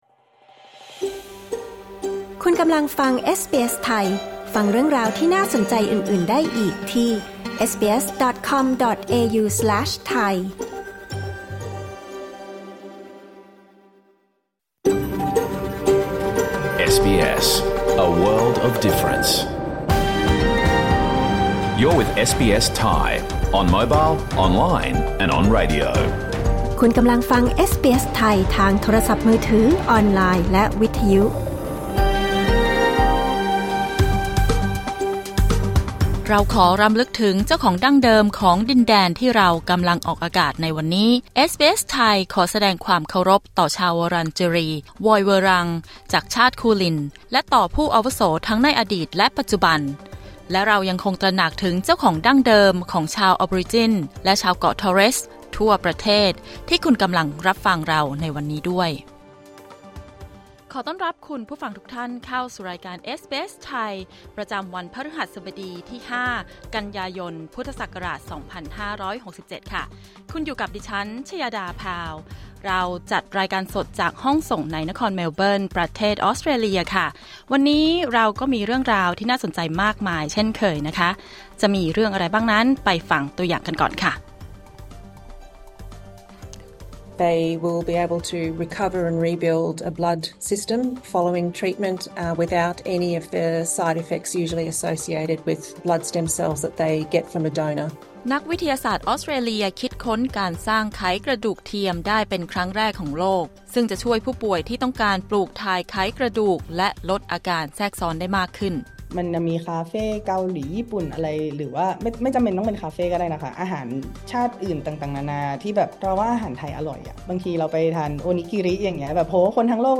รายการสด 5 กันยายน 2567